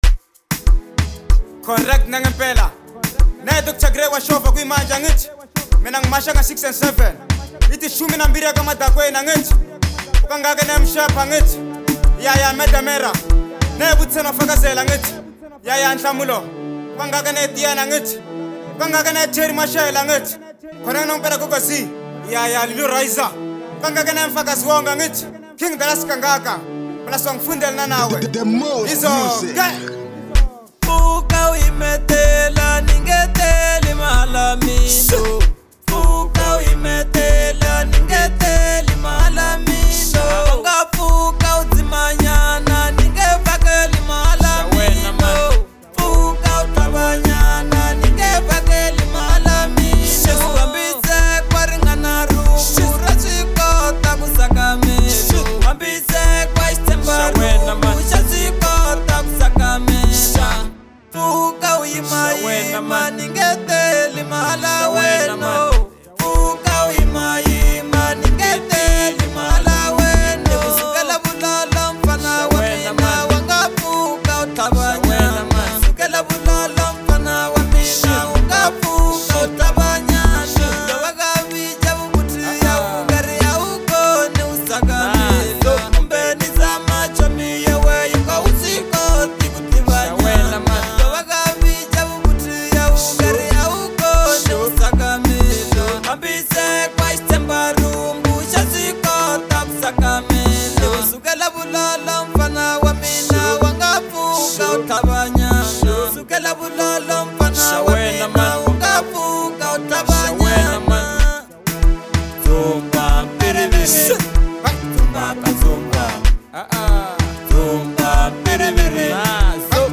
Genre : Bolo House